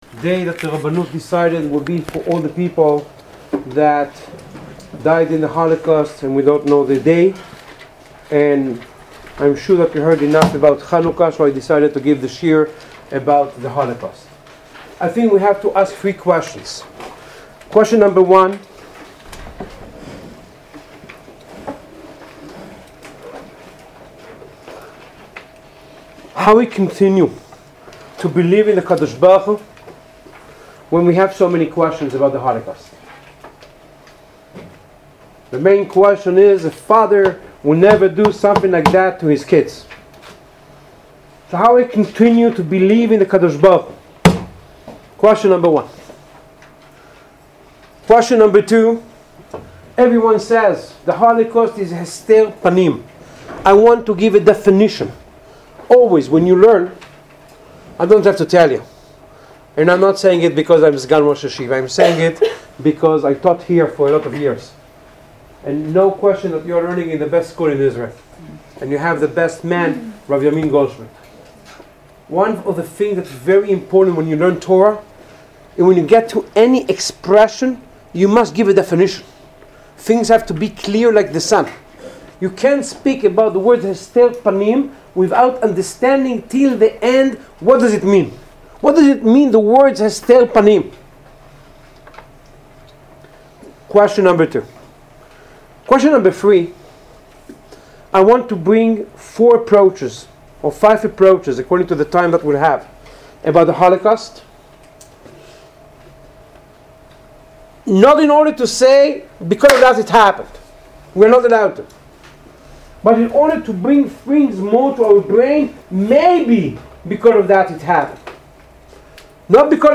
Listen to the Shiur: Listening to shiurim on this website requires the Windows Media Player.